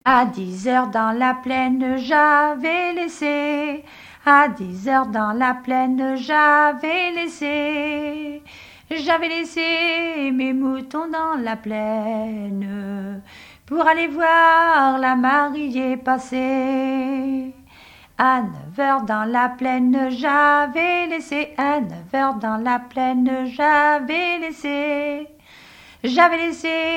Témoignages sur le mariage et chansons traditionnelles
Pièce musicale inédite